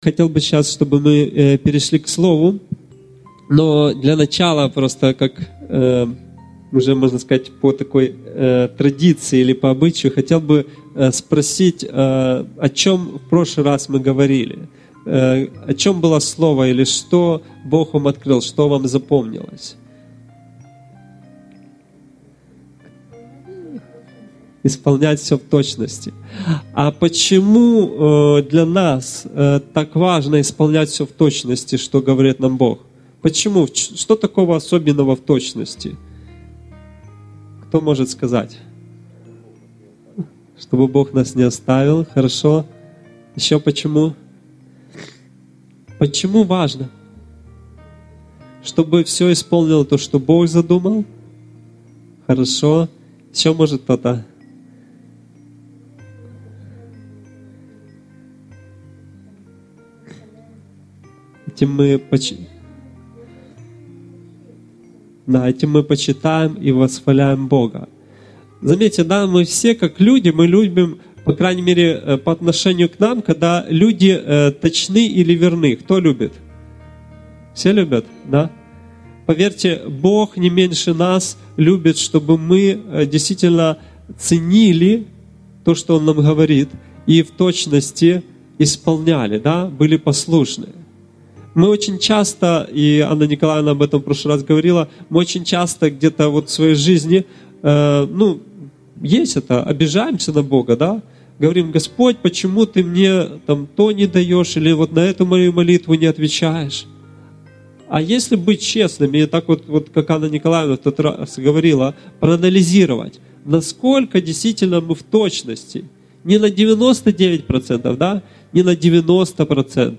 Актуальна проповідь